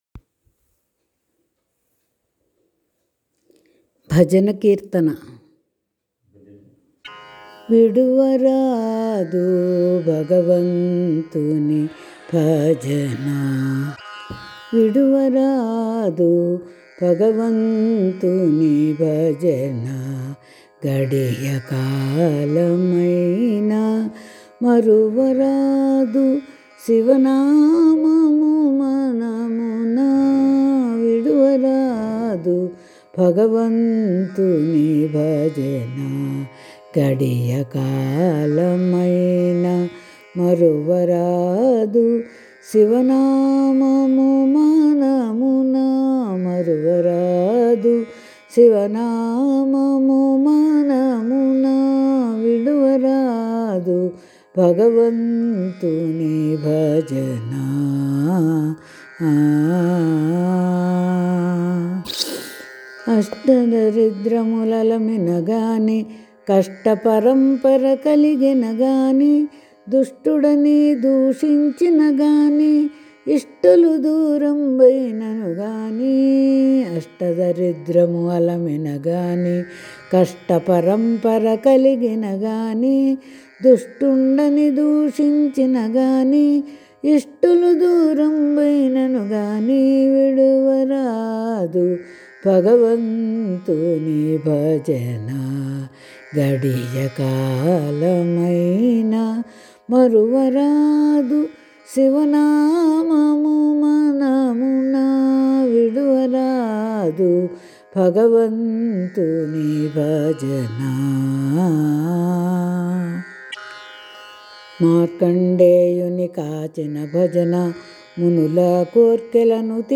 భజన పాట https
Bajana.mp3